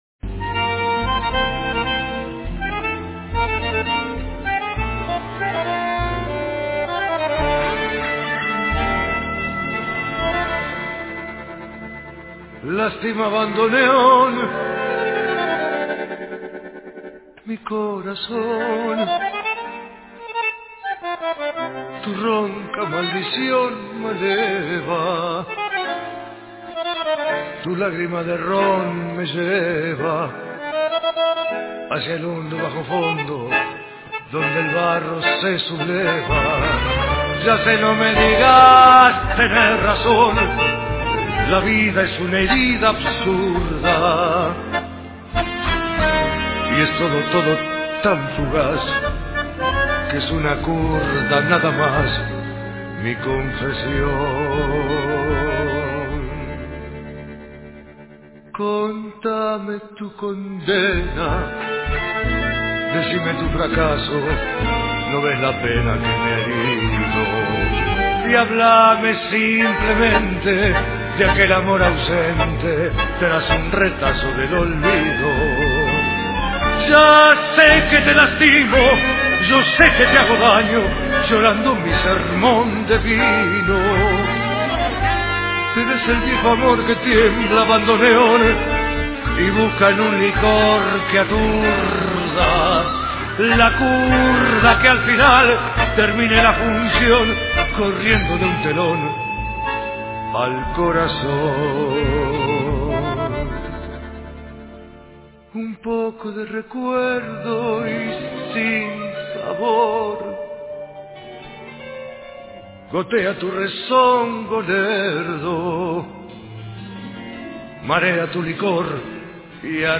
Poema escrito bajo inspiración del tango